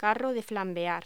Locución: Carro de flambear
voz